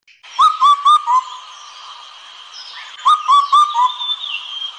四声杜鹃鸟鸣声
四声杜鹃鸟鸣声。